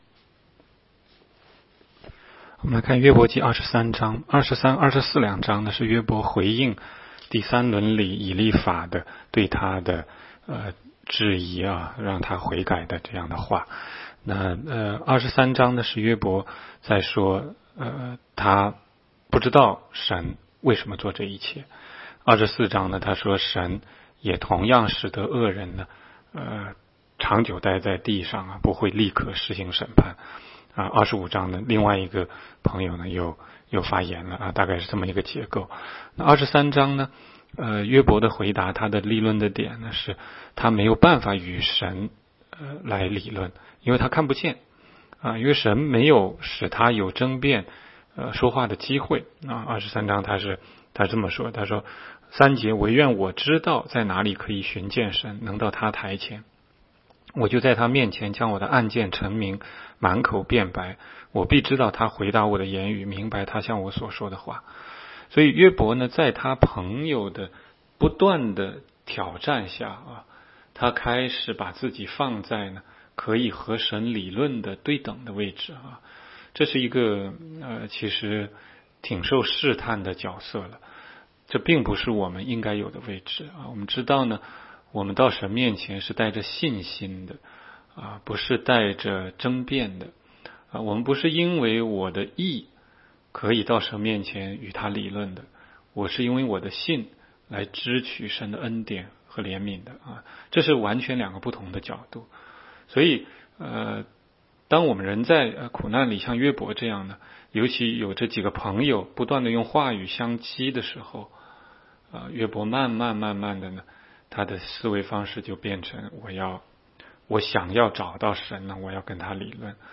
16街讲道录音 - 每日读经-《约伯记》23章